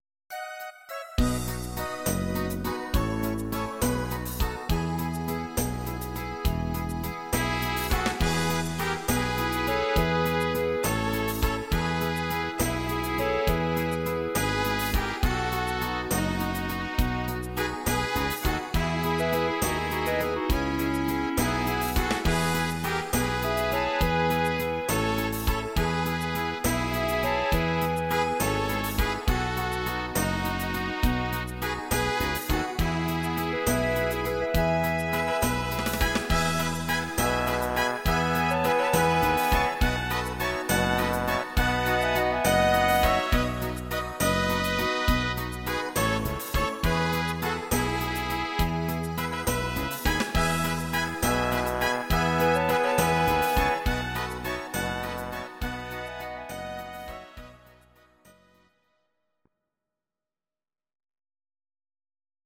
Audio Recordings based on Midi-files
German, Duets, Traditional/Folk, Volkstï¿½mlich